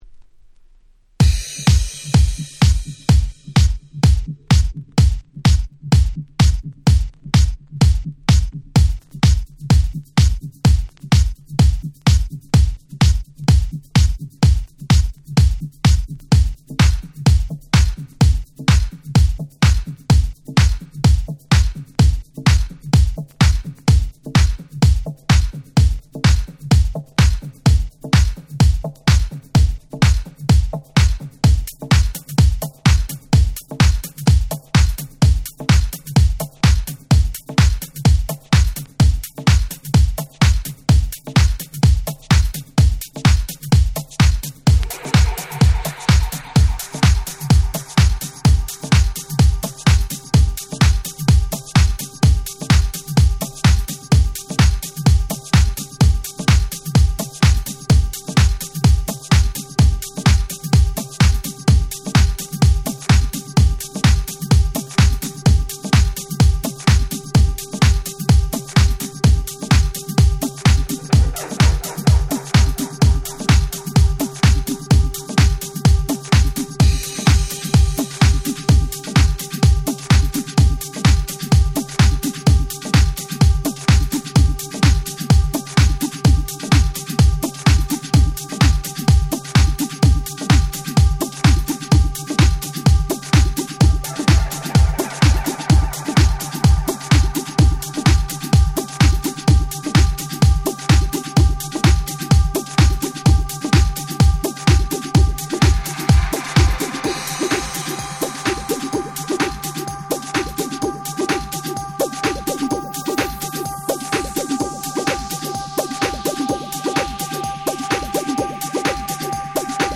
00' Super Nice Latin House !!
大人気定番ラテンハウス！！